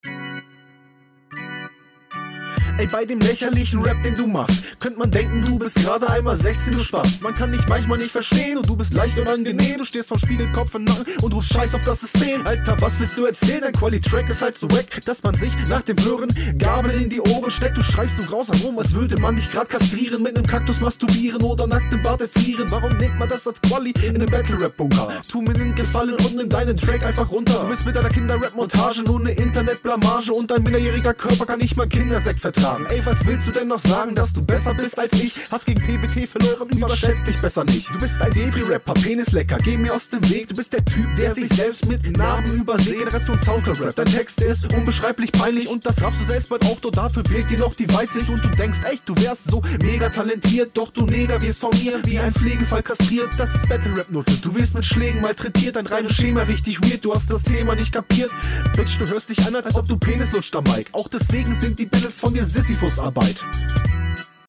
Finde den flow teils voll gut aber manchmal machst du noch Pausen so an den …
Geiler Beat